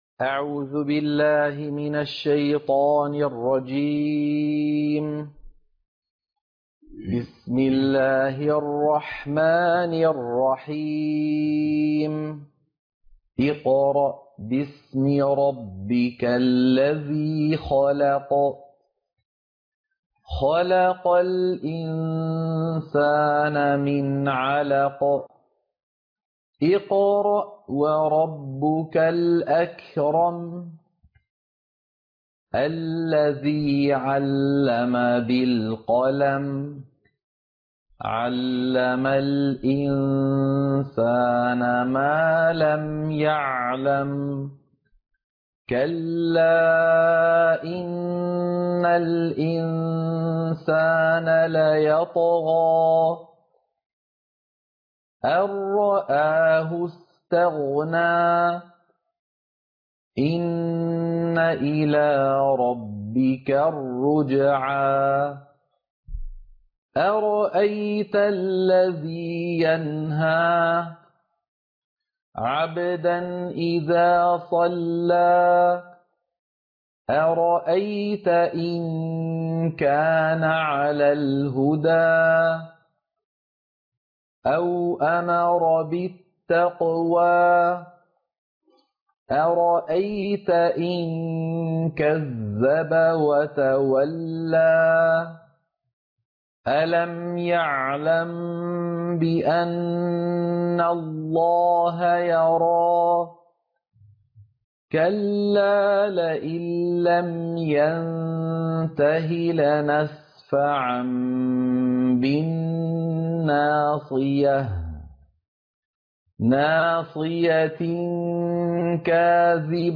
عنوان المادة سورة العلق - القراءة المنهجية